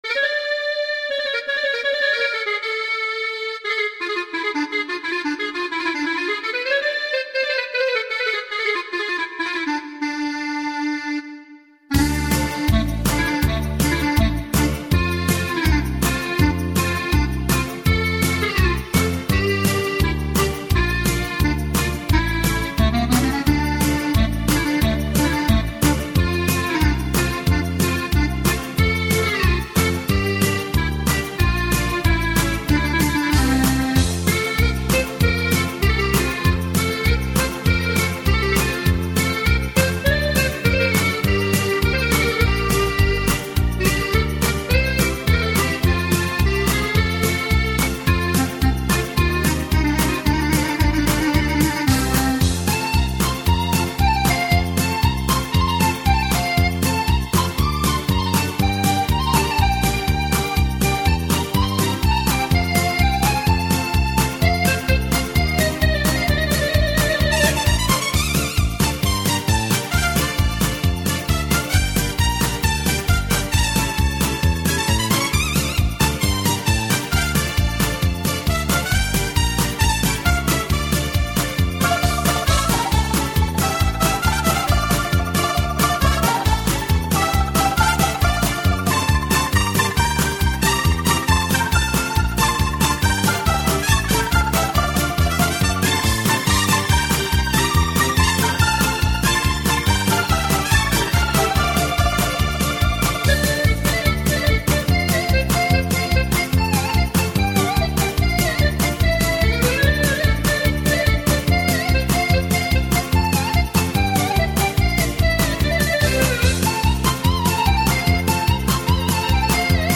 еврейская муз.танцы (закрыта)